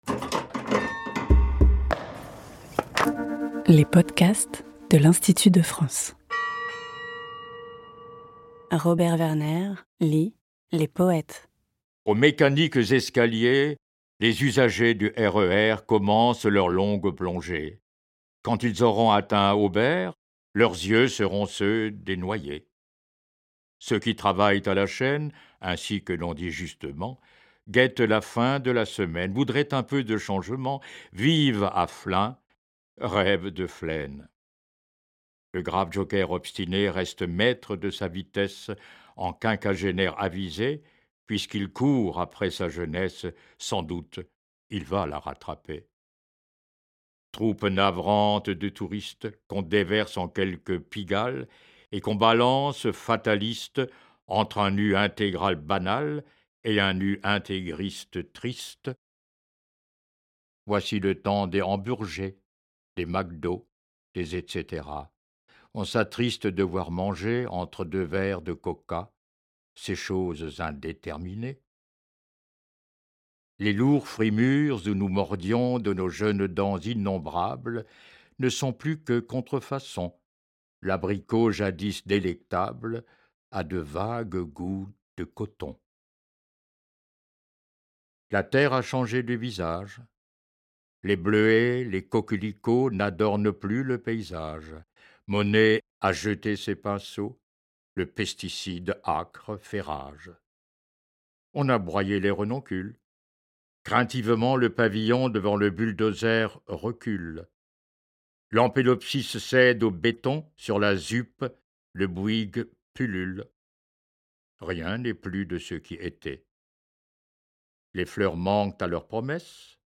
À voix lue